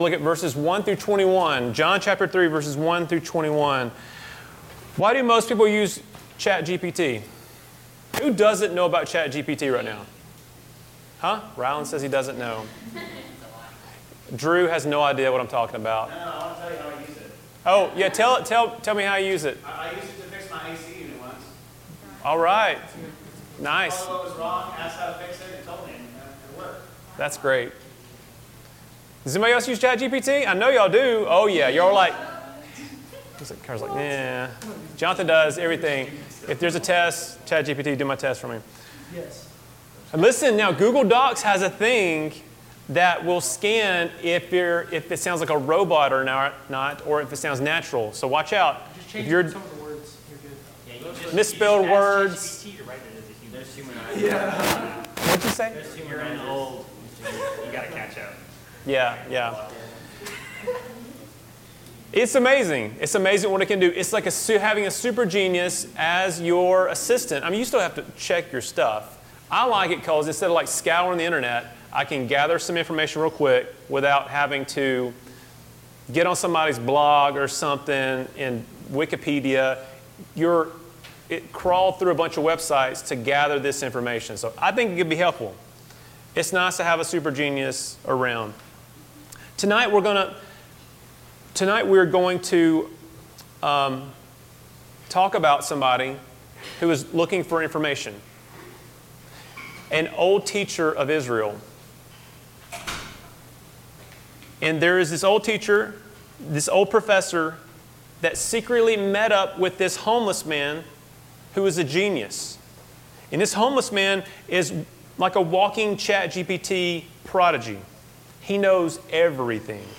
Messages from our Wednesday Night Student Services.